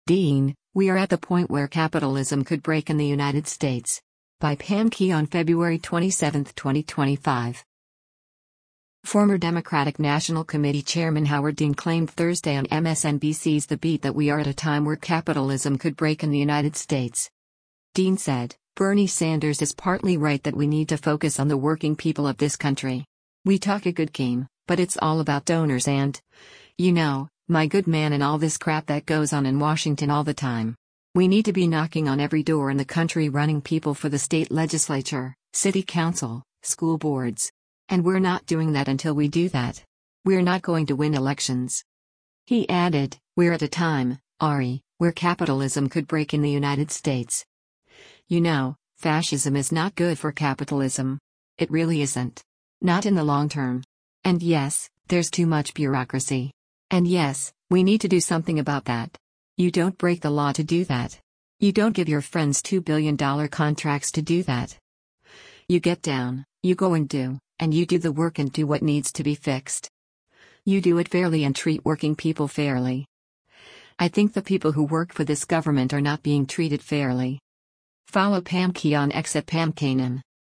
Former Democratic National Committee chairman Howard Dean claimed Thursday on MSNBC’s “The Beat” that we are at a time where “capitalism could break in the United States.”